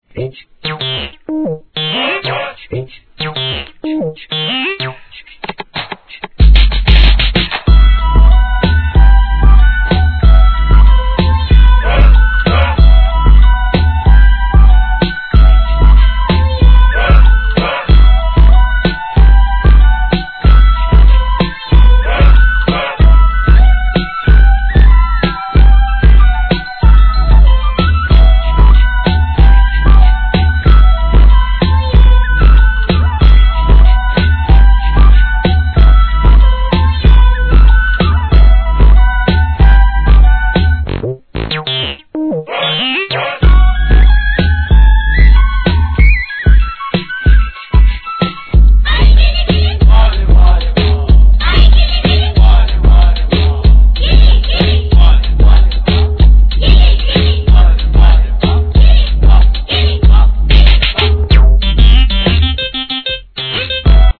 HIP HOP/R&B
エレクトロな質感に絶妙なスパイスで仕上げた2001年シングル!!